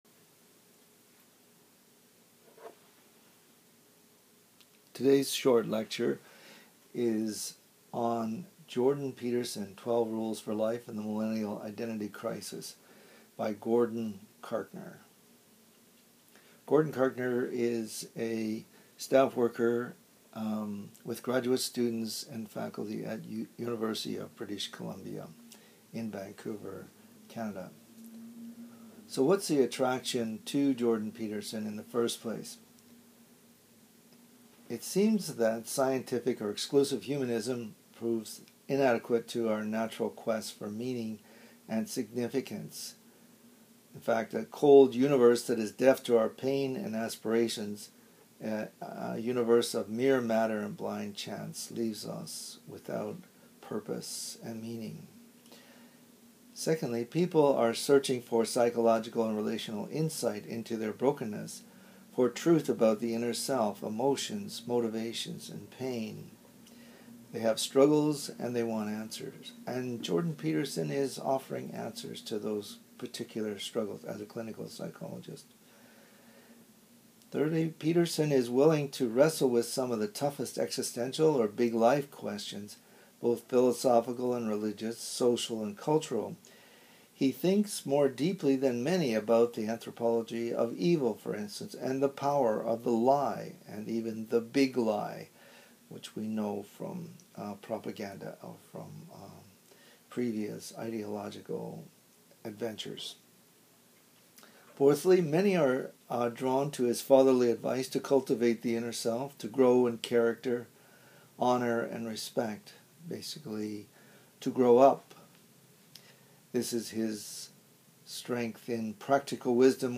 Panel Discussion on Jordan Peterson